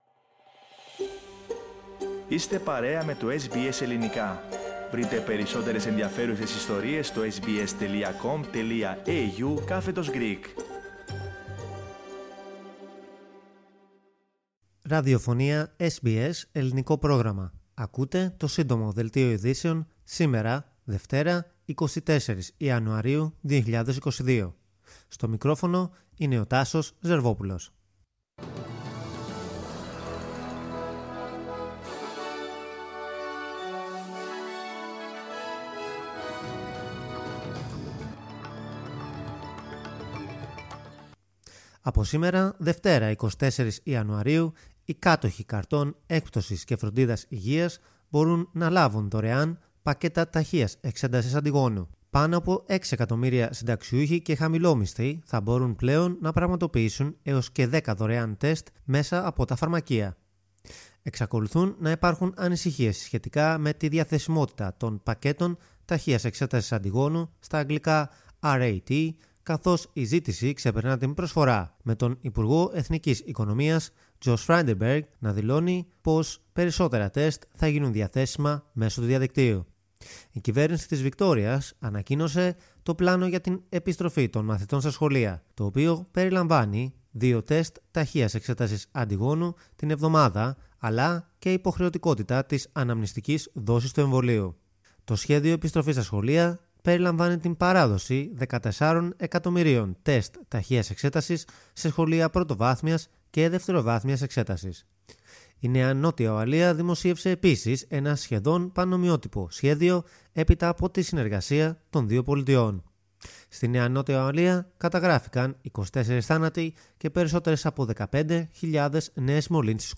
News Flash - Σύντομο Δελτίο
News flash in Greek.